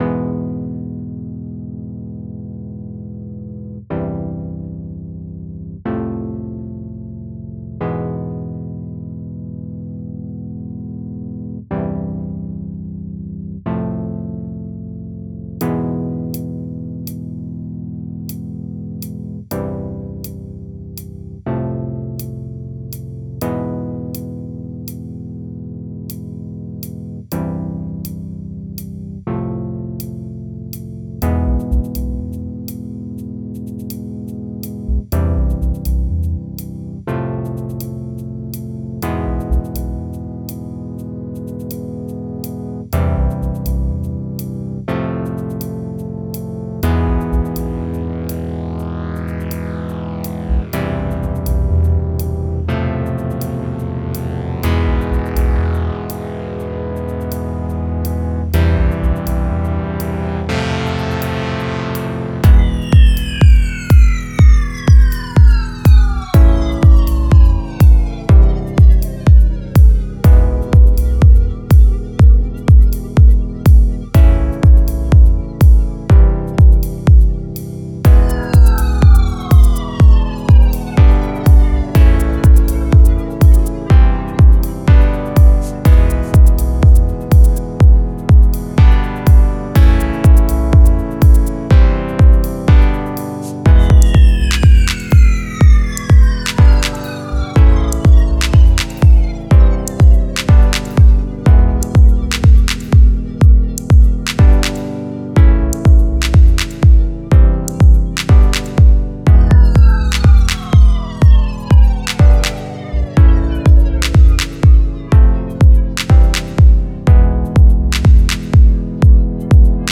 lounge - rythmique - danse - corporate - dj